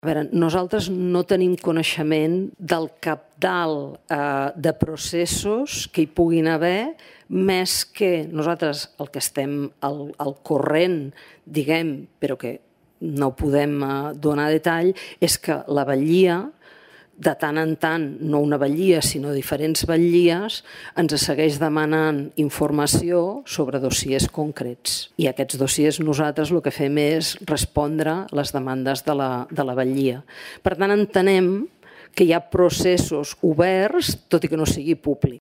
Ho ha assegurat La presidenta de l’Agència Estatal de Resolució d’Entitats Bancàries, Sílvia Cunill  durant la seva compareixença davant la comissió legislativa de Finances del Consell General, on ha fet balanç de l’estat del procés de liquidació concursal de l’entitat.